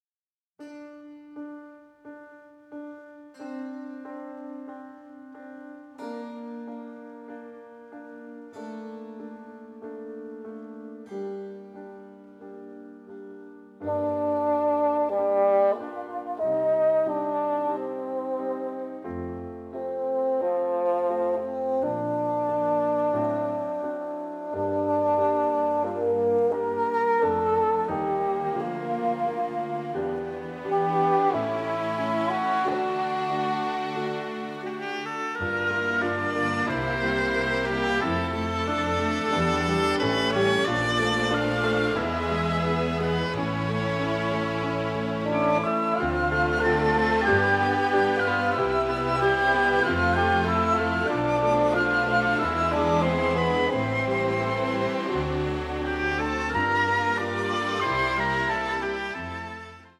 beautiful, nostalgic